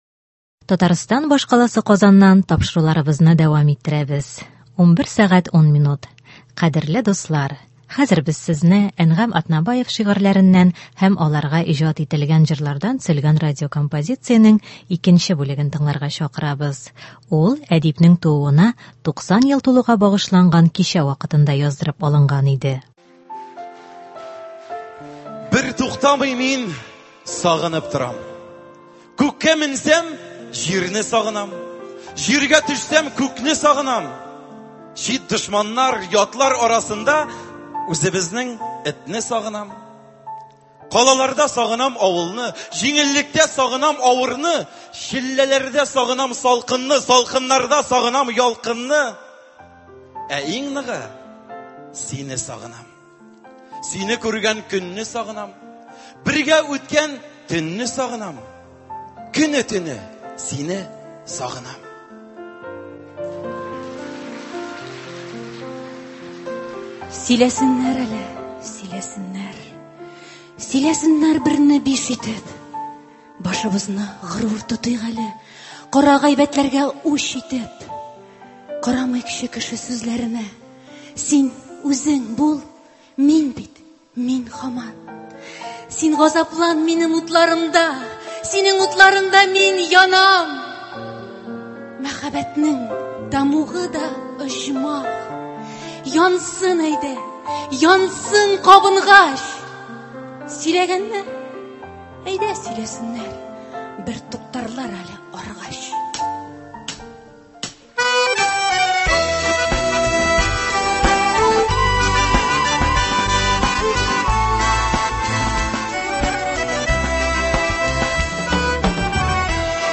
Ул – Әнгам Атнабаев шигырьләреннән һәм аларга иҗат ителгән җырлардан төзелгән радиокомпозиция.
Хәзер яңгыраячак композициягә килсәк, ул әдипнең тууына 90 ел тулуга багышланган кичә вакытында яздырып алынган иде.